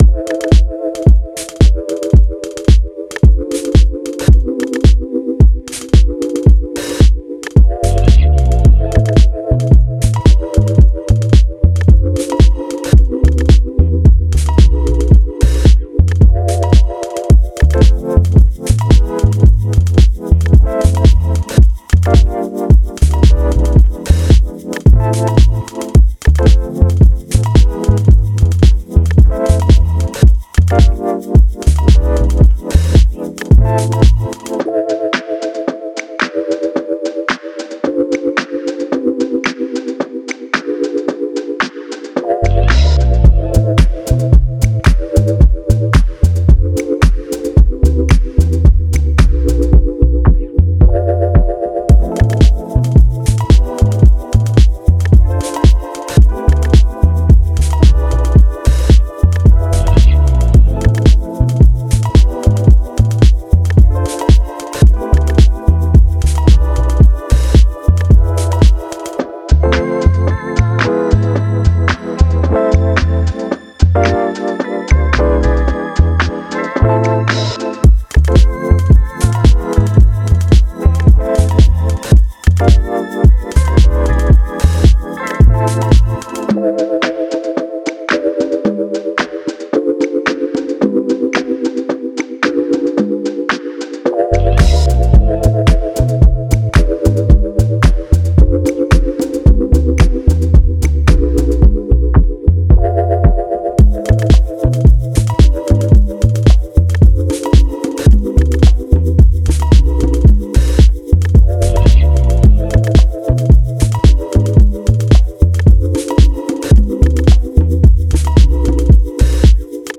Groovy, uptempo beat with ticking synths and wonky organ.